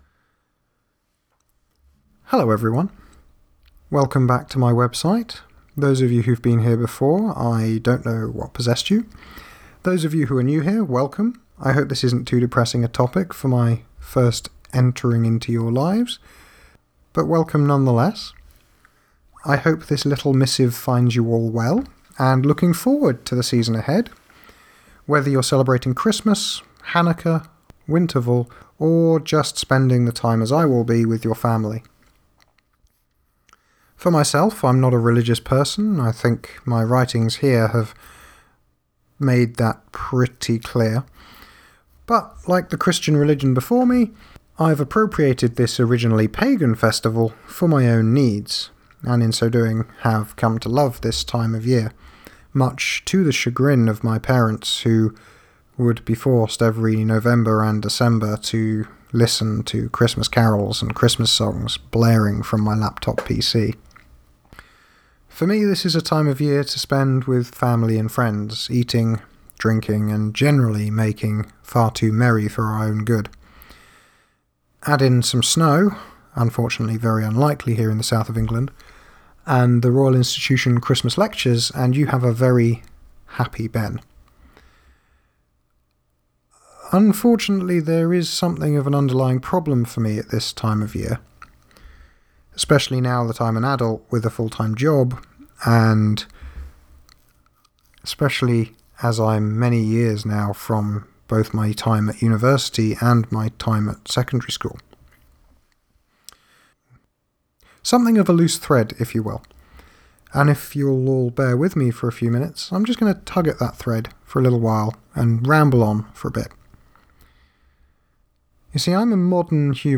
All Humans are Islands… A slightly sober Christmas message from me
I do hope you’ll all forgive me for it’s dour tone!